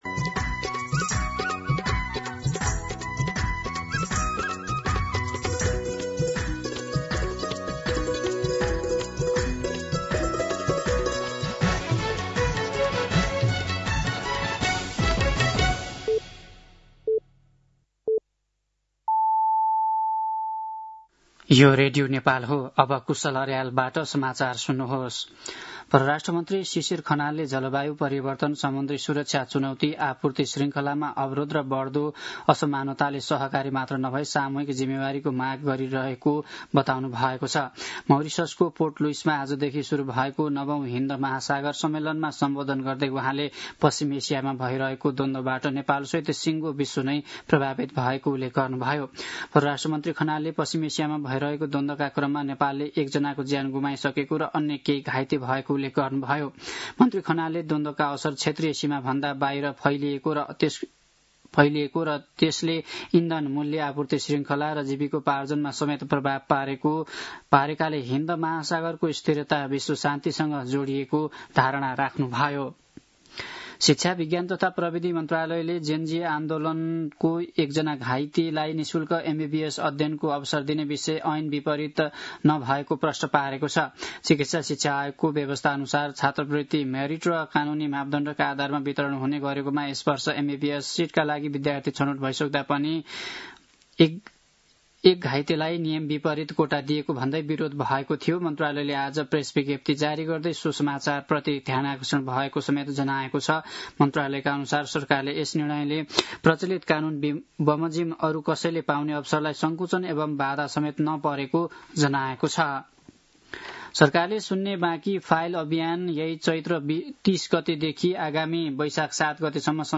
दिउँसो ४ बजेको नेपाली समाचार : २८ चैत , २०८२
4pm-News-28.mp3